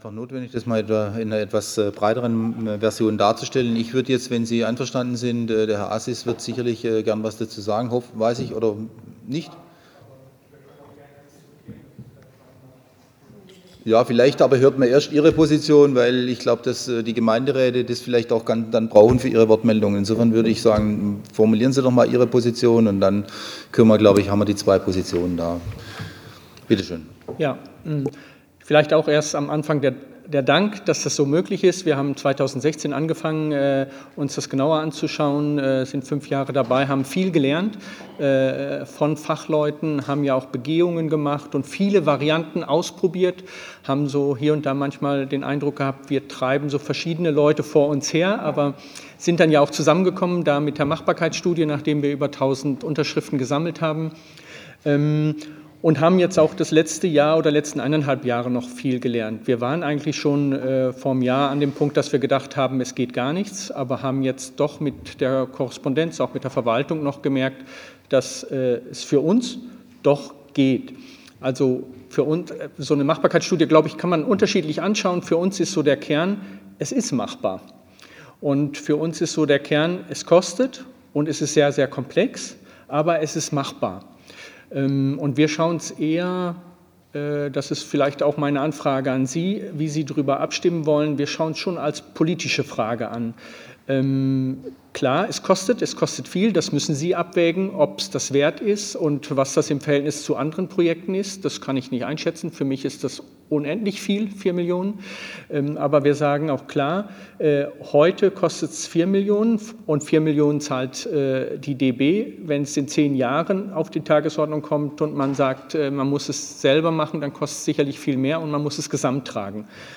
5. BaUStA Ausschuss in Freiburg: Leben unter Hochspannungsleitungen soll bleiben - Verlegung und Vergrabung für Stadt zu teuer